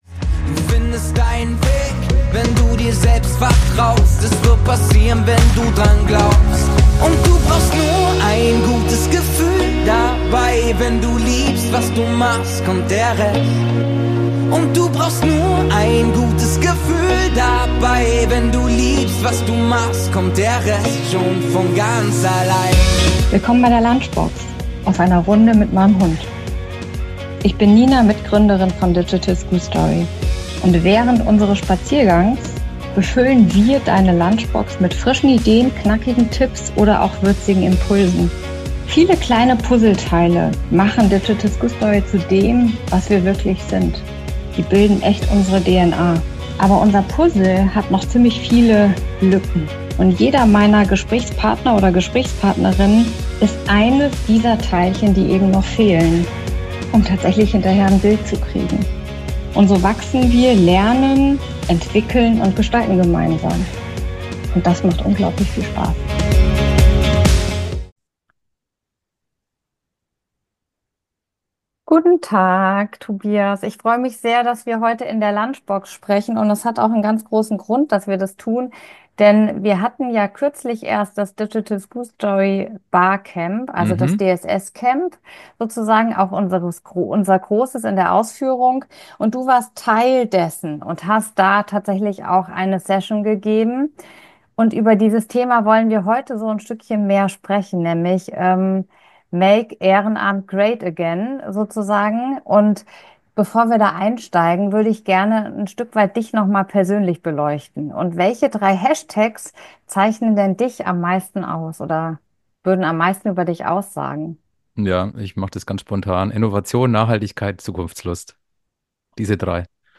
- komm mit auf eine Runde mit meinem Hund und mir!